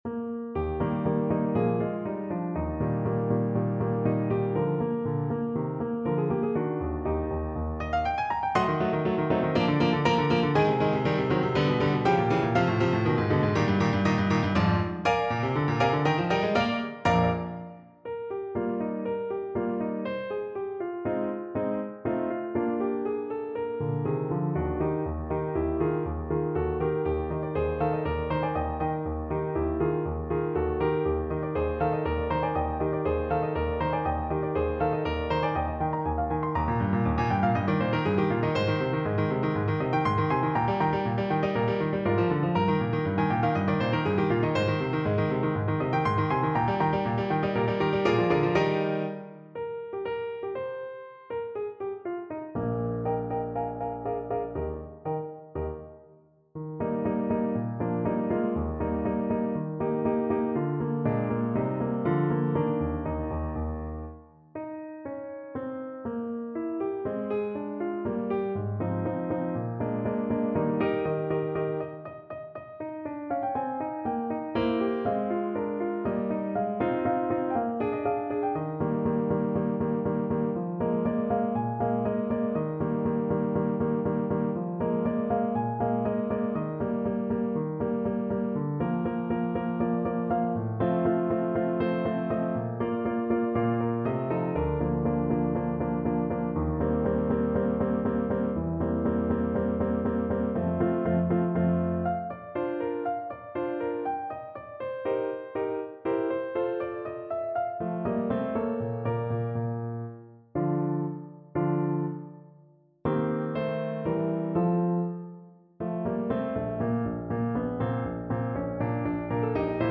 K. 447 in a reduction for Horn in F and piano.
Allegro (View more music marked Allegro)
4/4 (View more 4/4 Music)
Classical (View more Classical Tenor Horn Music)